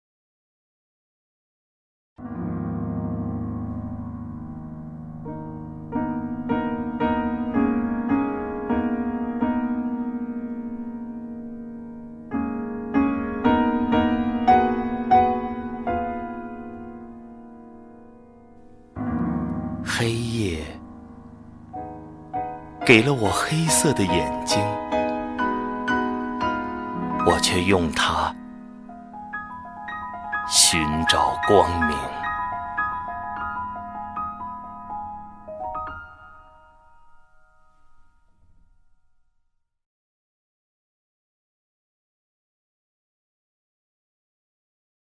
赵屹鸥朗诵：《一代人》(顾城) 顾城 名家朗诵欣赏赵屹鸥 语文PLUS